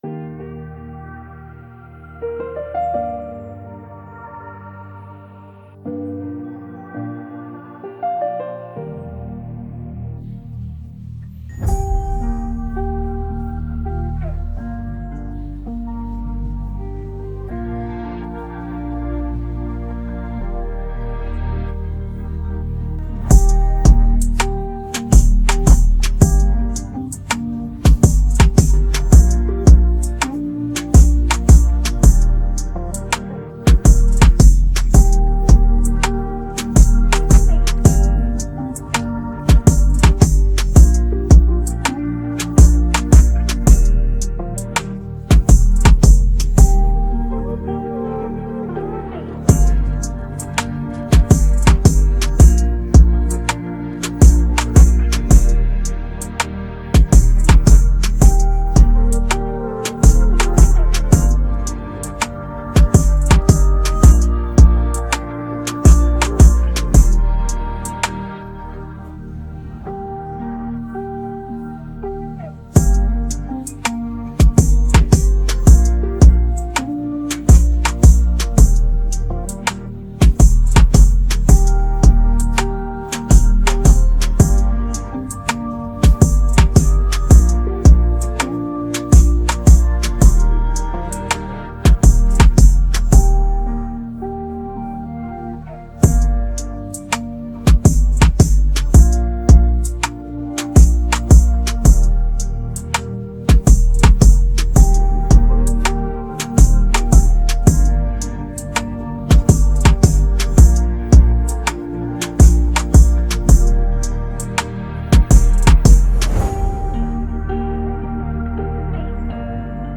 Afro popHip hophiphop trap beats